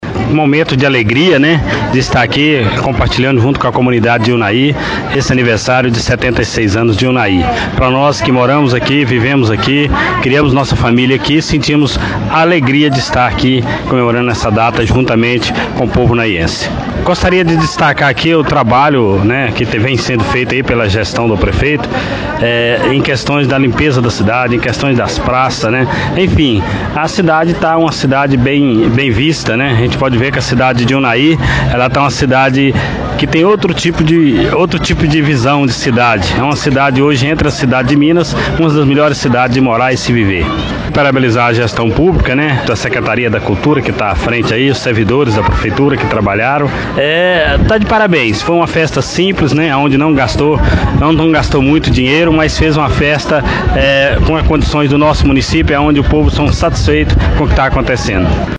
Nossa reportagem esteve presente e ouviu as autoridades que participaram ativamente das atividades.